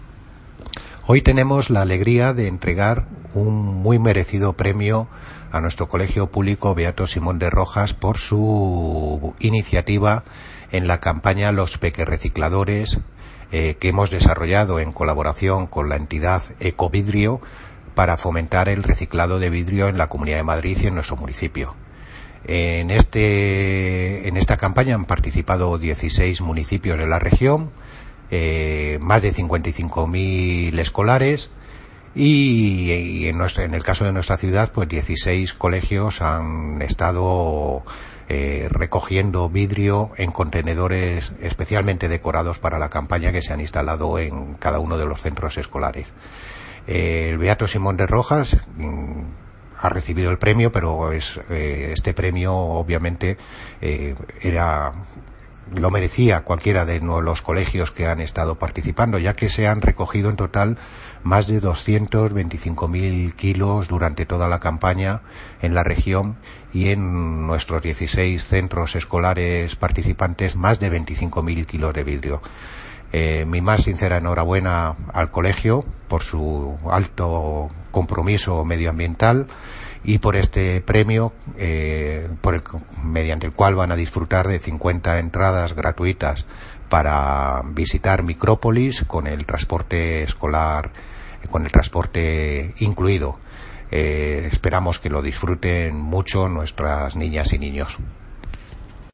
Audio - Miguel Ángel Ortega (Concejal de Medio Ambiente, Parques y Jardines y Limpieza Viaria) Sobre Campaña